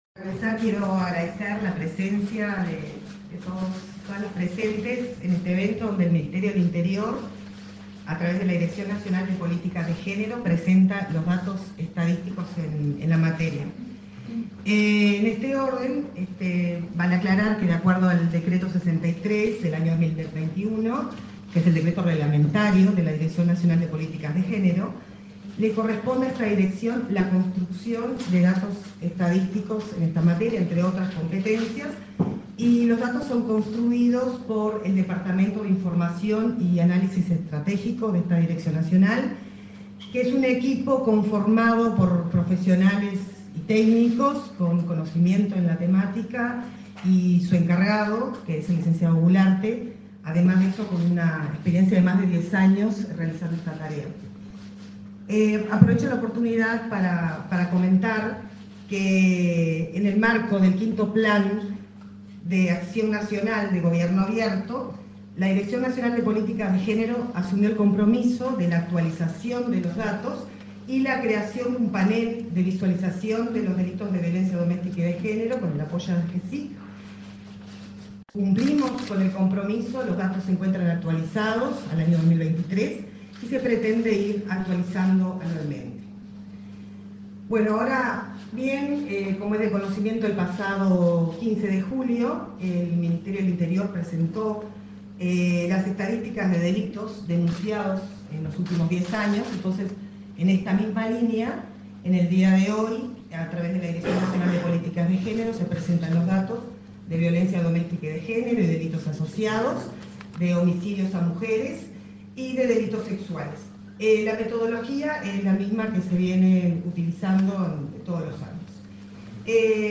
Acto de presentación de datos sobre violencia doméstica y de género 07/08/2024 Compartir Facebook X Copiar enlace WhatsApp LinkedIn El Ministerio del Interior presentó, este 7 de agosto, los principales indicadores de violencia doméstica y de género, mediante estudio elaborado por la Dirección Nacional de Políticas de Género, respecto al primer semestre de 2024, en comparación a semestres de años anteriores. En la oportunidad, disertaron la directora nacional de Políticas de Género, Angelina Ferreira, y el subsecretario del Ministerio, Pablo Abdala.